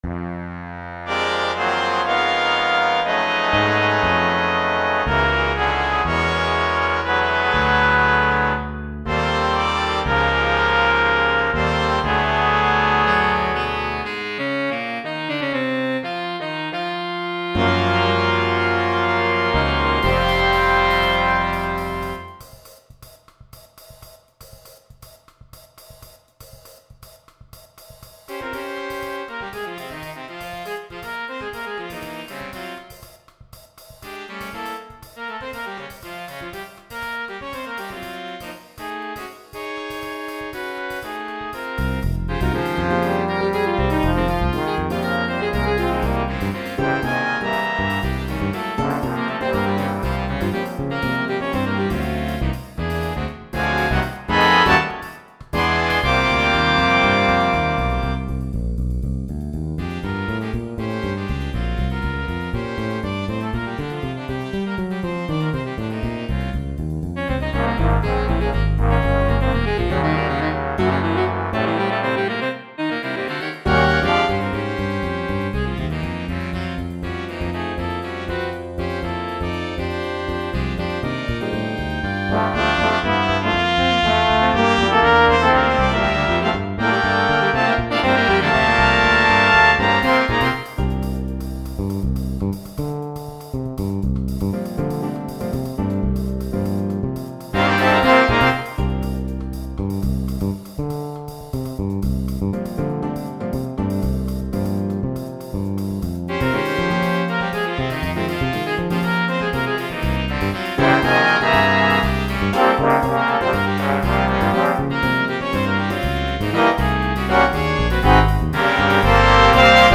This samba chart features a trio of trumpet, alto, and tenor with solo duties in the trumpet and alto parts.
(Sorry, only a computer playback sound file.